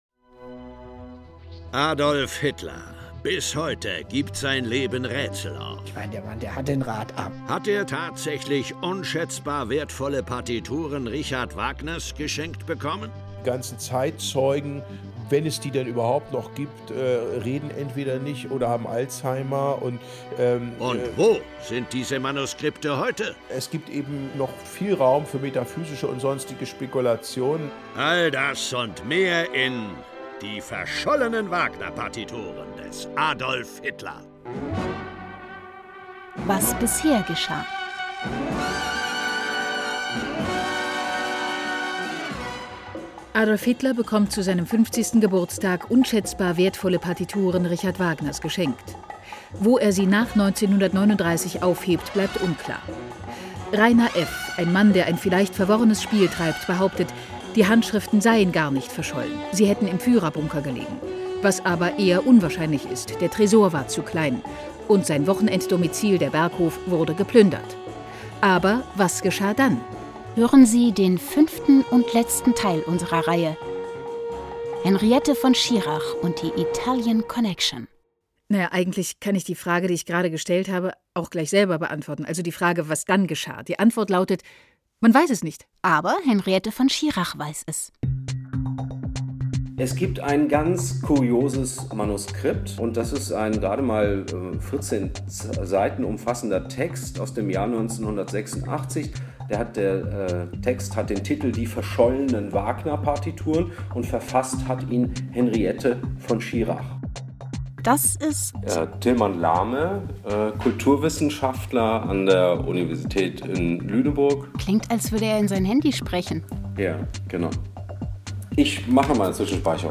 parallel zu dem 54-minuten-stück, quasi als bonustracks und epidote, entstanden fünf fünfminütige hörspiele. ausgestrahlt wurden sie in den ard-kultursendern im rahmen des ard-festivals.